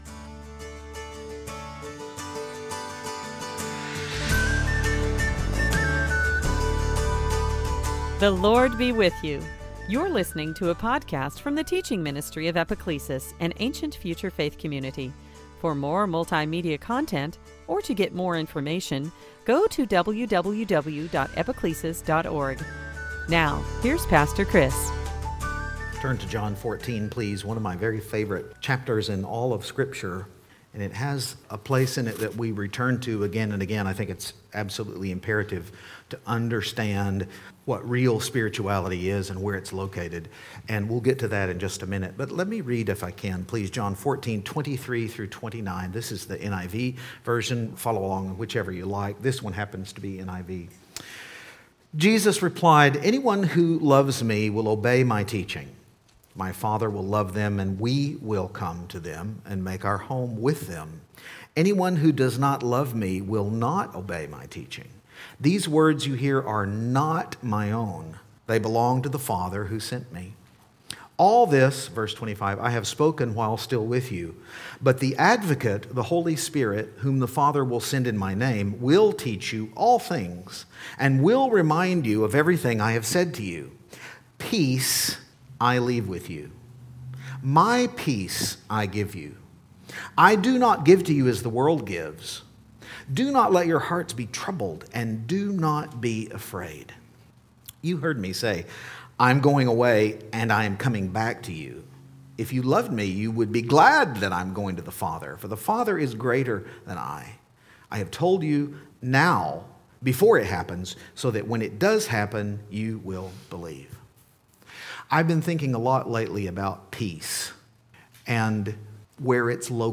2022 Sunday Teaching Fruit of the Spirit headlines heart let monkeypox peace set the mind Eastertide